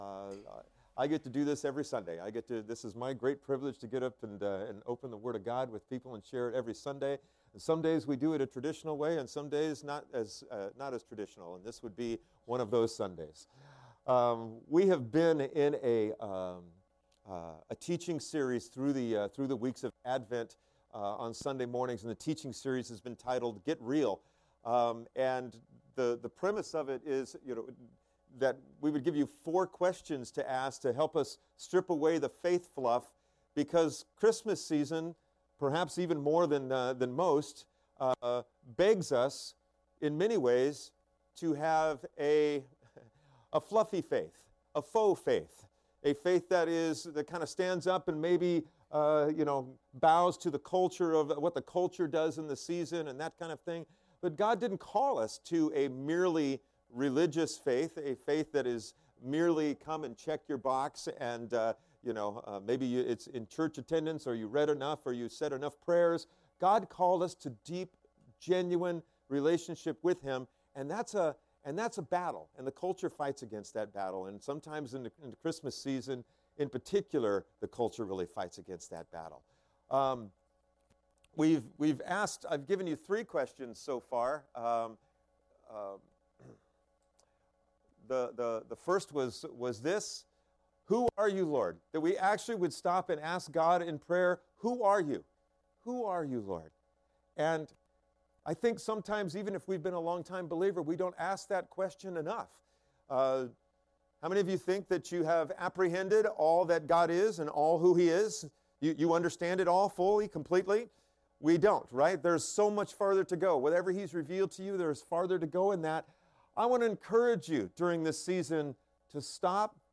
Sermons - Lighthouse Covenant Church